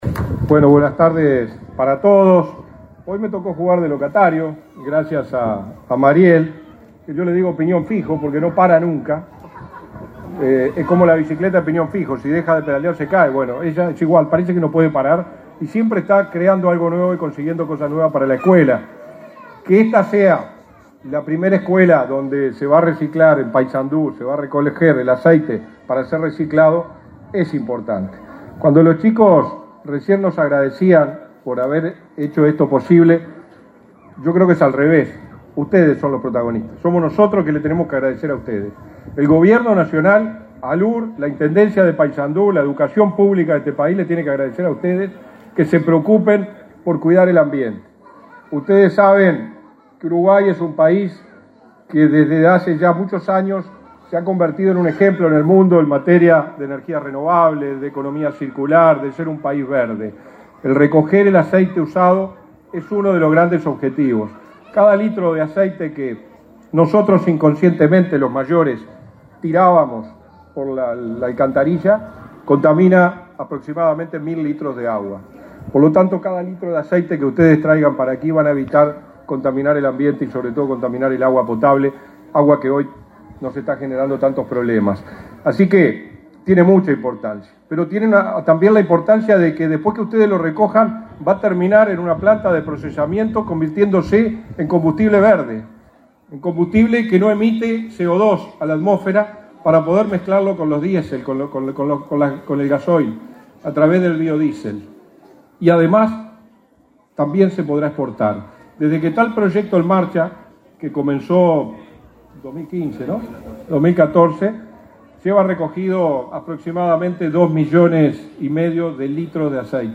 Palabras del subsecretario de Industria, Energía y Minería, Walter Verri
Palabras del subsecretario de Industria, Energía y Minería, Walter Verri 05/06/2023 Compartir Facebook Twitter Copiar enlace WhatsApp LinkedIn La Administración Nacional de Educación Pública (ANEP) y Ancap inauguraron, este 5 de junio, en el marco del Día Mundial del Medio Ambiente, un plan de reciclaje de aceite de cocina en la escuela n.° 25, San Félix, de Paysandú.